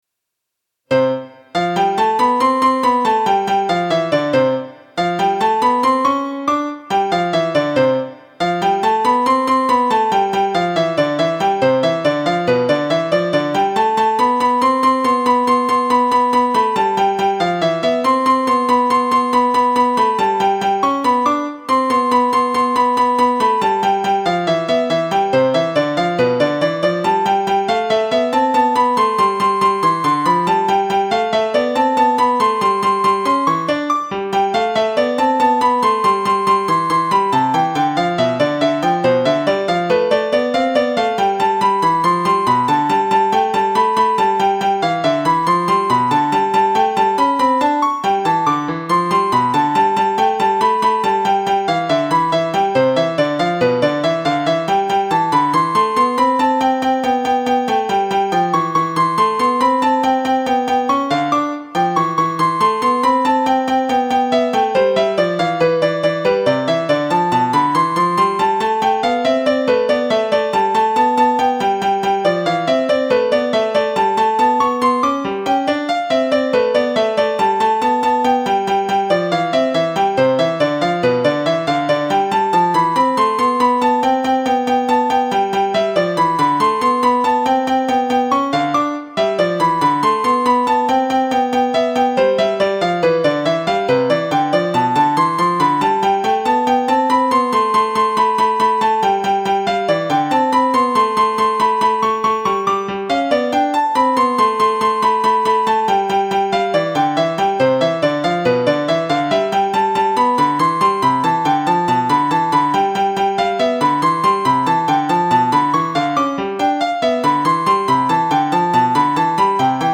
『トルコ行進曲』をディープラーニングさせて2声でAI作曲する方法を考えてみました。ニューラルネットワークで生成する時系列の特性を利用しています。
無修正です。キーを変えています。